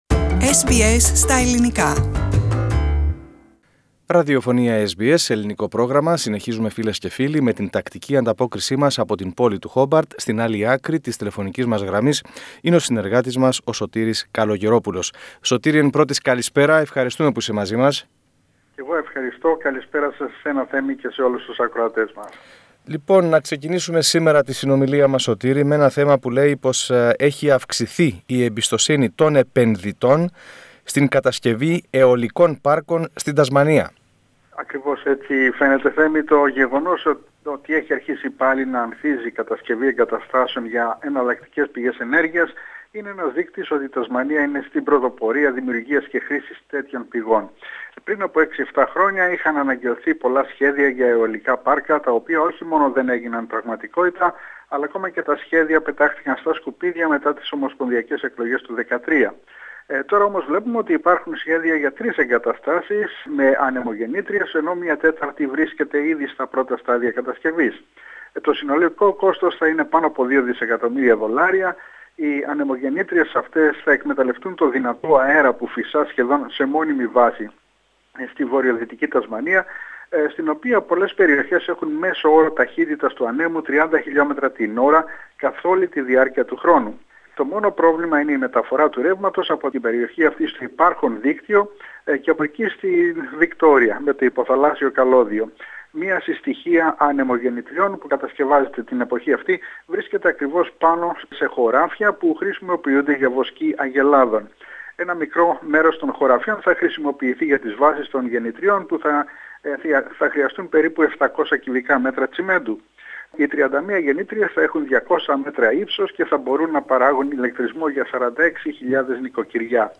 εβδομαδιαία ανταπόκριση από την Τασμανία